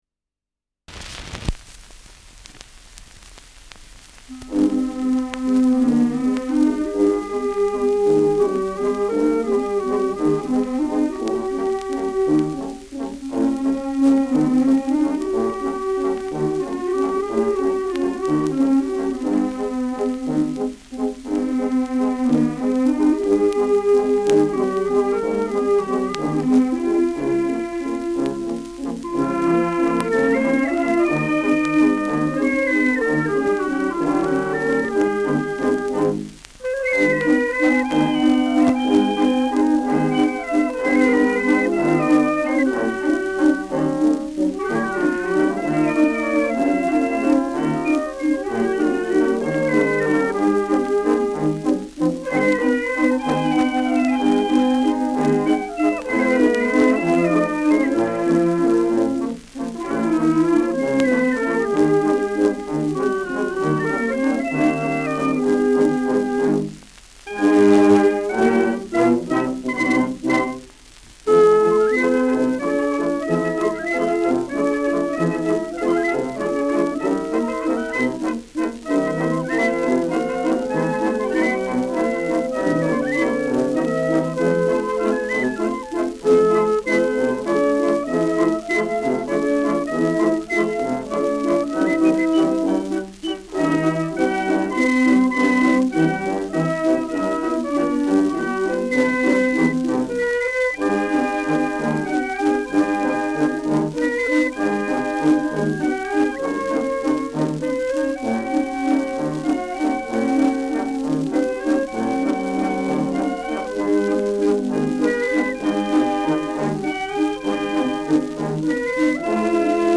Schellackplatte
Stärkeres Grundrauschen : Gelegentlich leichtes bis stärkeres Knacken